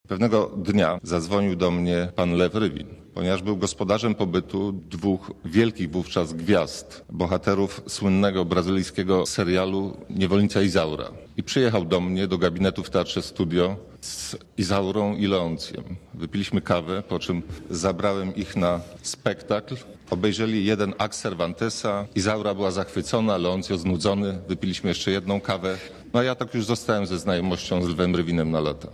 Mówi Waldemar Dąbrowski (230Kb)